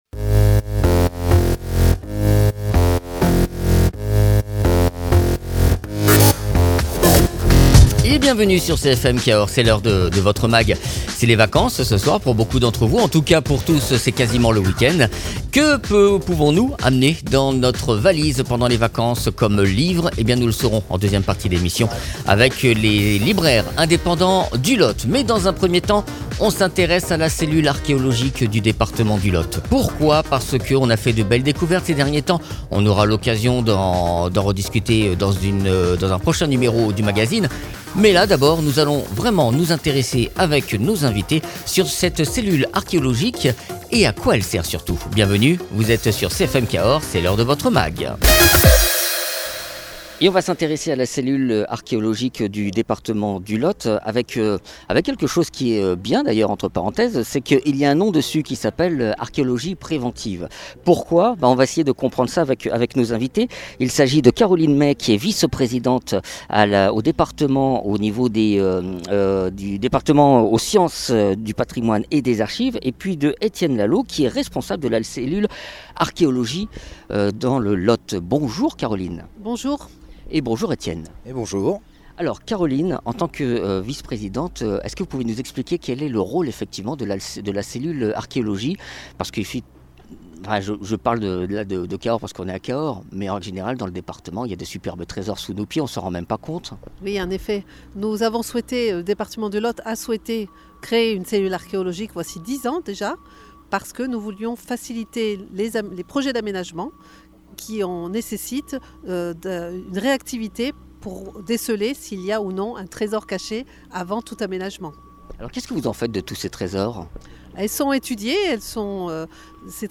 Invité(s) : Caroline Mey-Fau, vice-présidente en charge du Patrimoine historique, de l’Archéologie préventive et des Archives départementales du Lot.